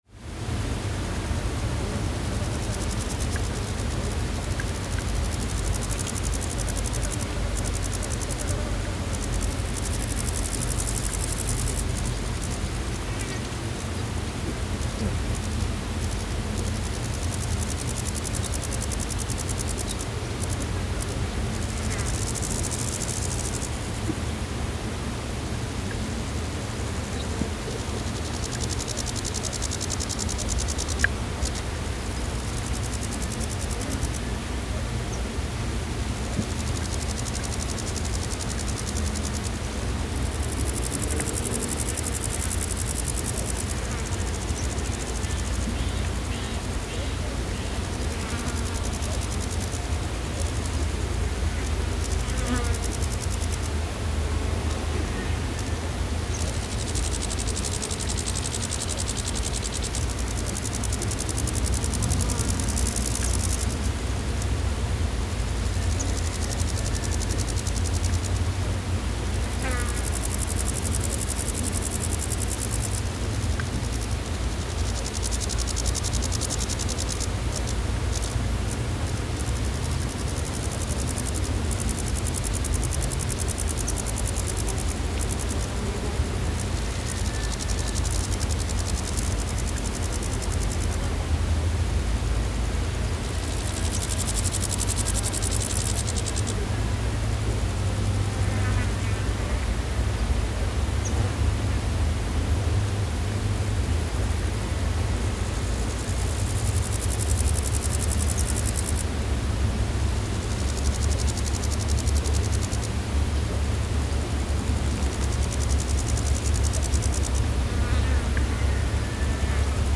The sounds of different insects formed an afternoon melody in the desert.
Desert insects at Paquime reimagined